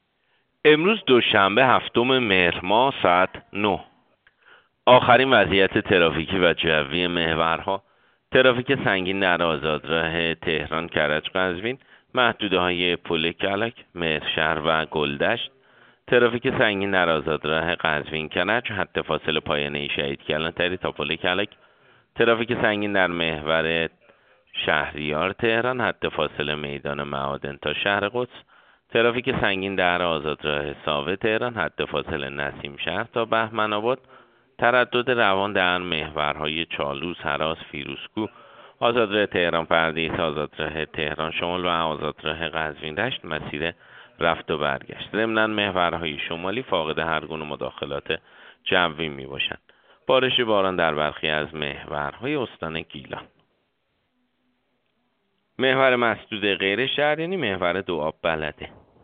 گزارش رادیو اینترنتی از آخرین وضعیت ترافیکی جاده‌ها ساعت ۹ هفتم مهر؛